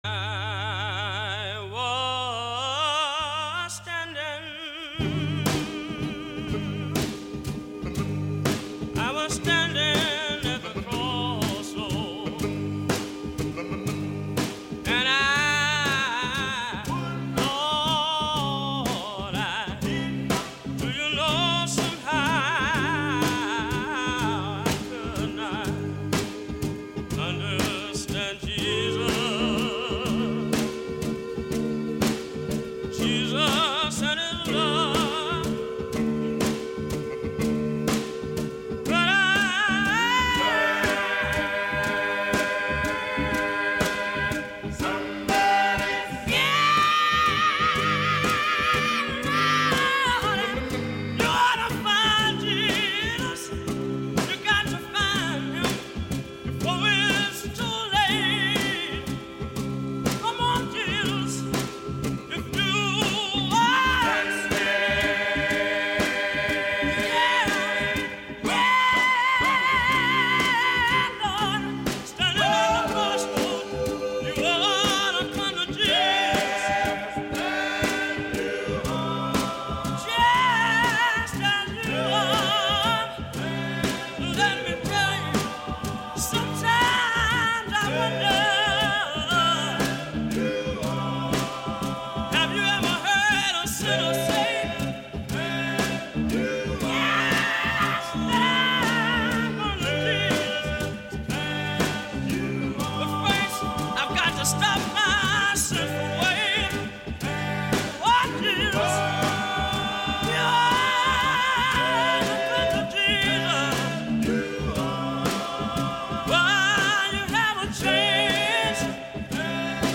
[Recorded in Charleston, South Carolina]
Private Cassette Recordings